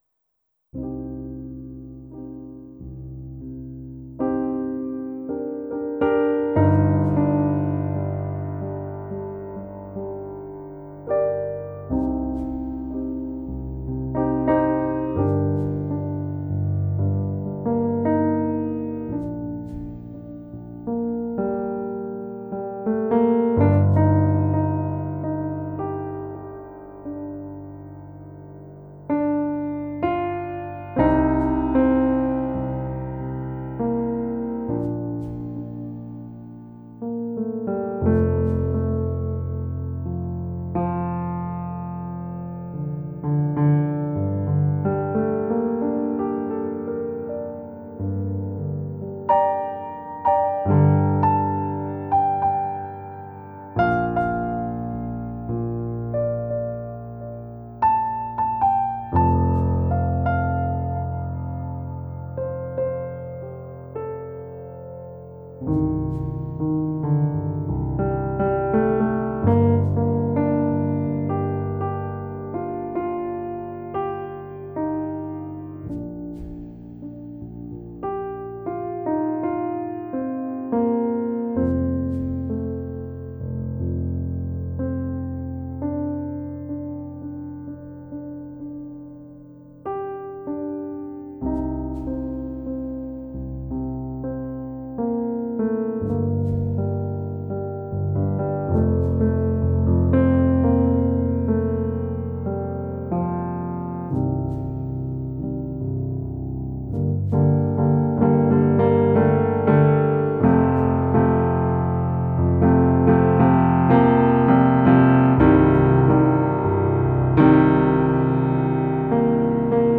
music / PIANO D-G